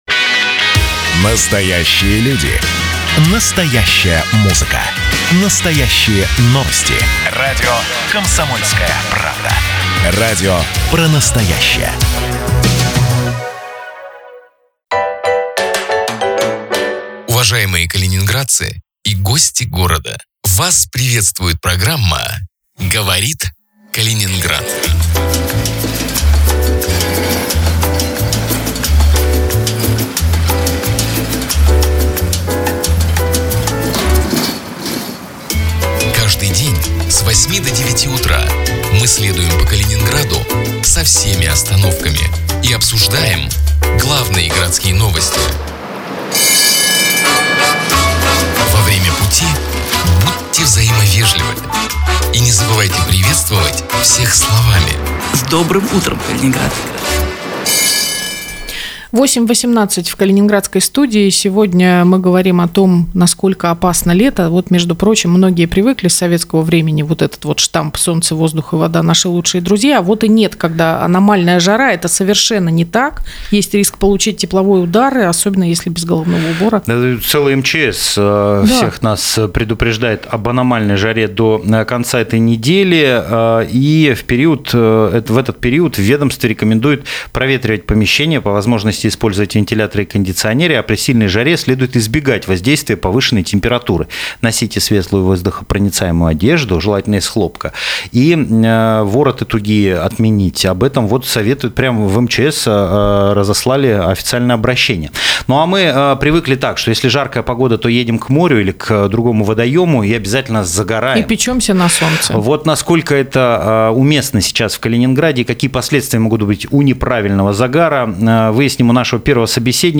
Каждое утро в прямом эфире обсуждаем городские новости.